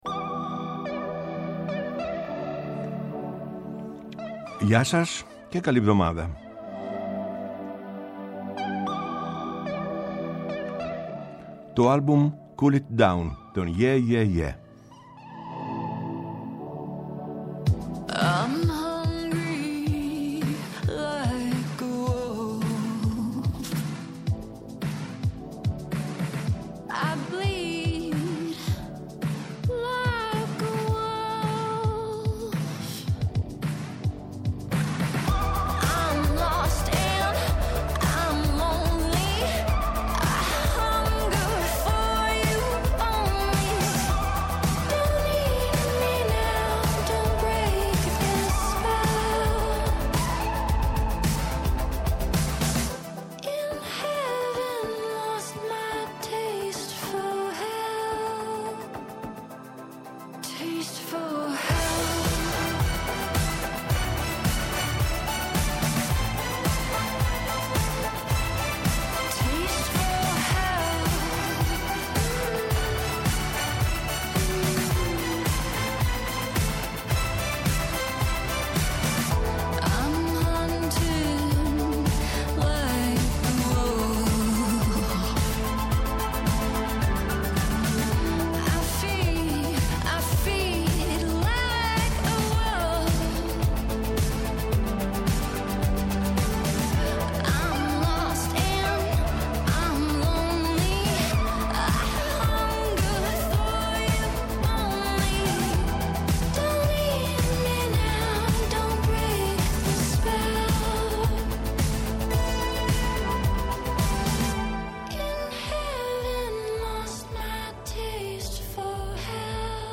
Η μακροβιότερη εκπομπή στο Ελληνικό Ραδιόφωνο!
Από το 1975 ως τον Ιούνιο 2013 και από το 2017 ως σήμερα, ο Γιάννης Πετρίδης βρίσκεται στις συχνότητες της Ελληνικής Ραδιοφωνίας, καθημερινά “Από τις 4 στις 5” το απόγευμα στο Πρώτο Πρόγραμμα